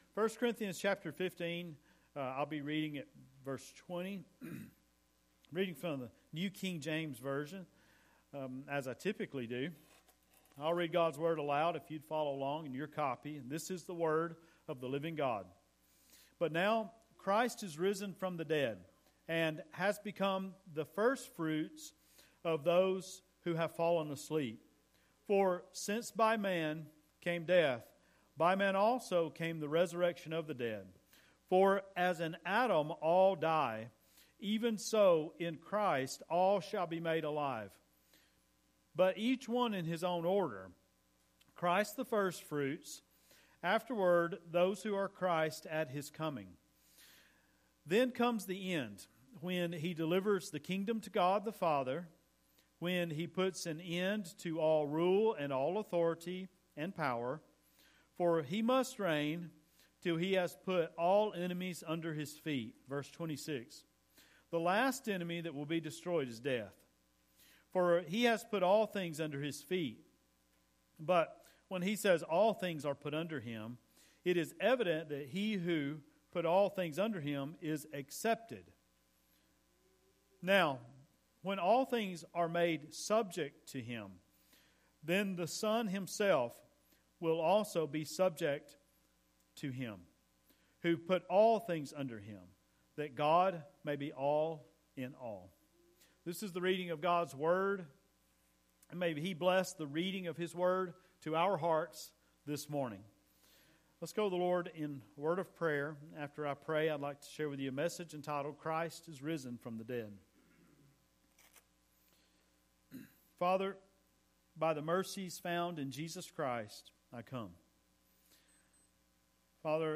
NLBC_Sermons - New Life Baptist Church